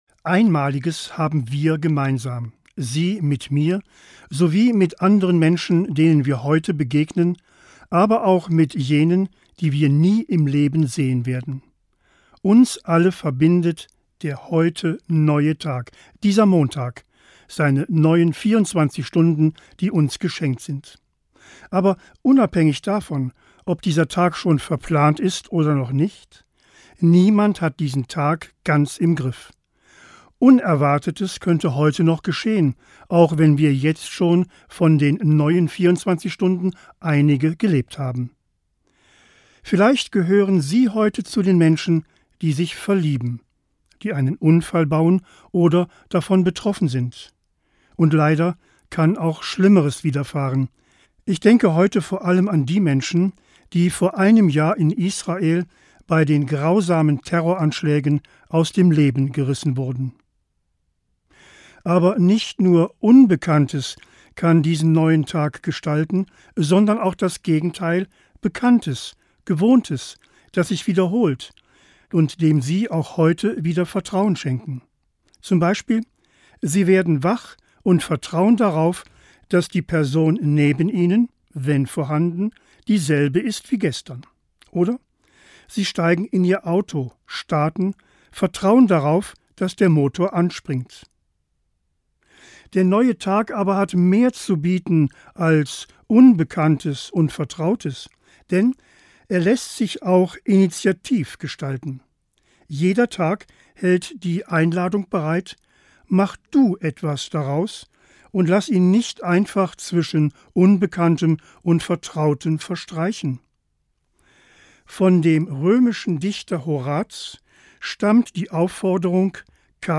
Morgenandacht 7.10.